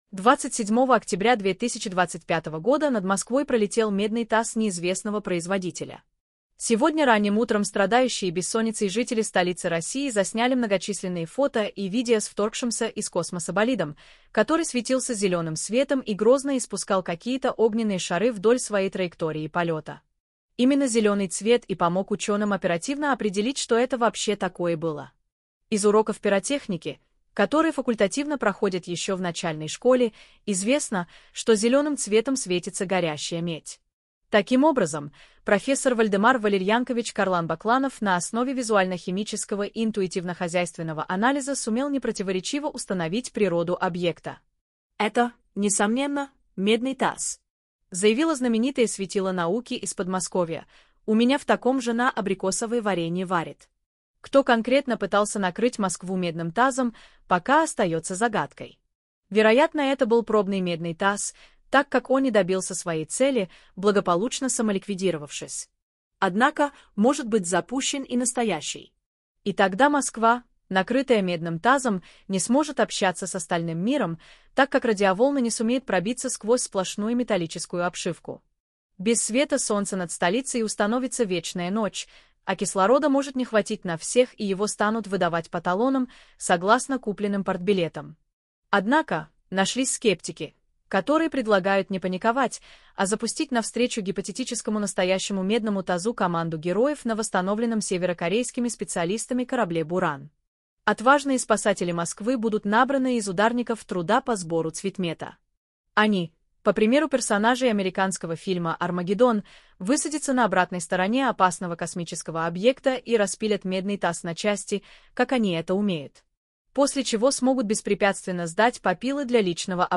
Голос «Дина», синтез речи нейросетью